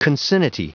Prononciation du mot concinnity en anglais (fichier audio)